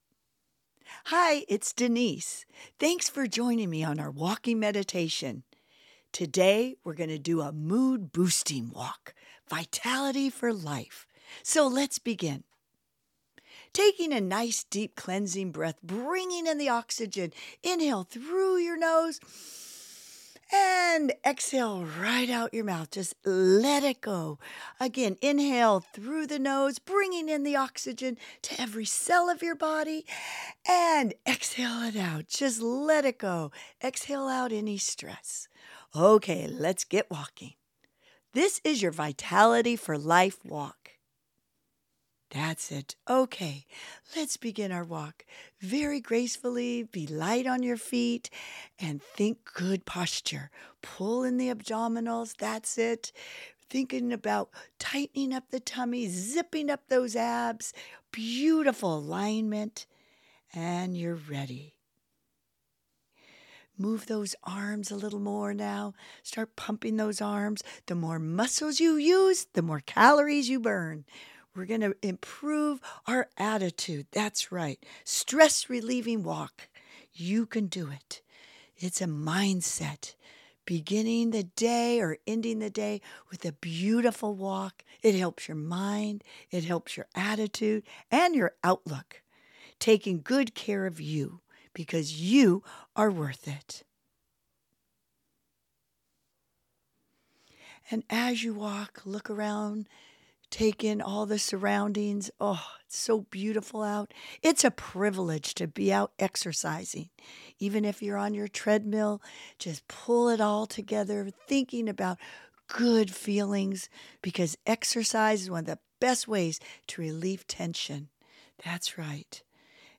Fit Over 50: Walk Off The Weight - Denise Austin's Mood Boosting Walking Meditation by Denise Austin | Feb 03, 2026 | Uncategorized Click HERE to start your 20-minute Mood Boosting Walking Meditation